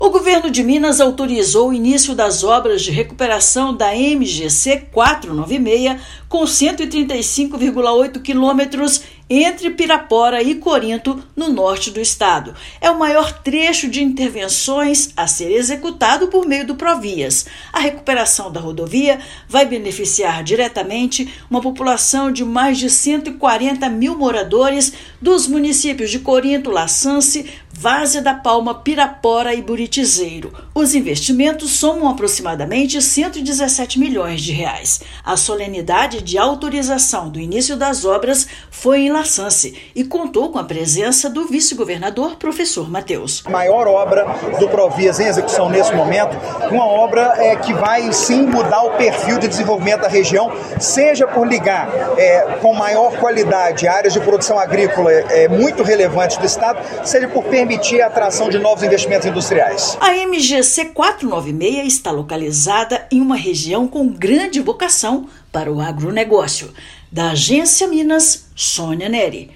Trecho tem 135,8 quilômetros de extensão e será a maior obra em execução no estado por meio do Provias, beneficiando mais de 140 mil pessoas. Ouça matéria de rádio.